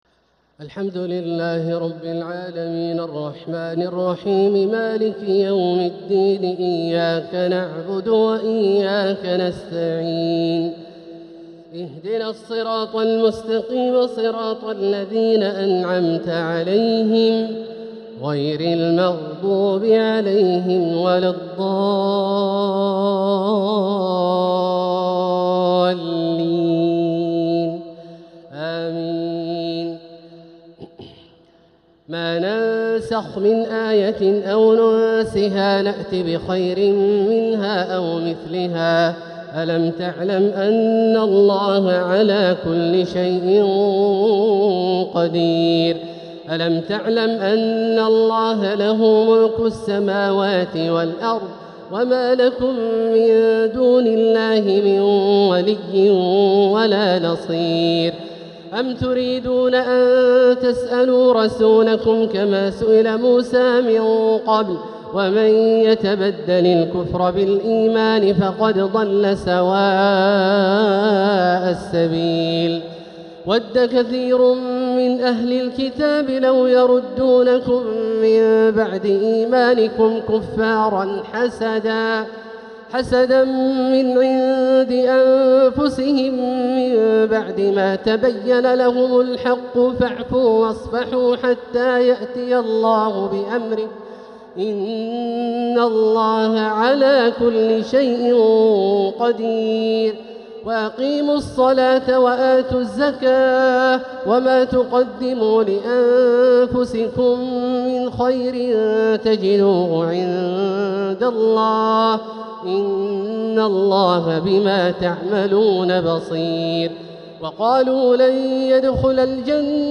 تراويح الليلة الثانية للشيخ عبدالله الجهني رمضان ١٤٤٧ هـ ترتيل ماتع محبر من سورة البقرة {106-167} > تراويح 1447هـ > التراويح - تلاوات عبدالله الجهني